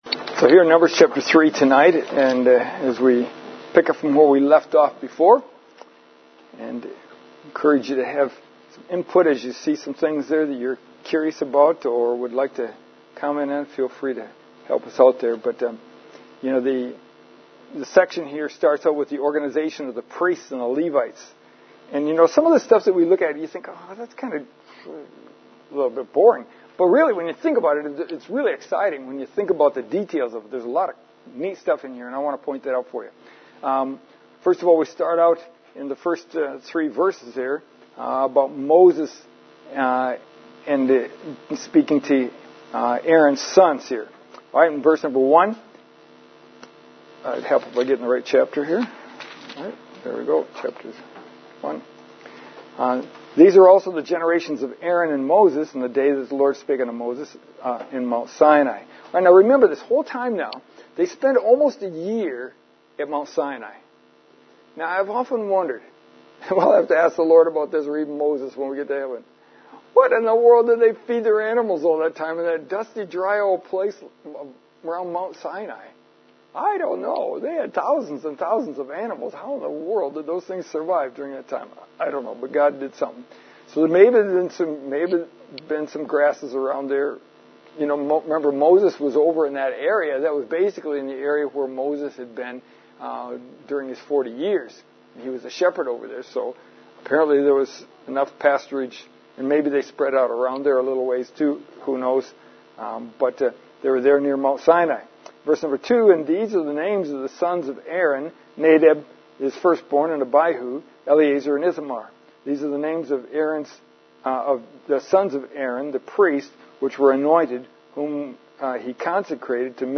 Lesson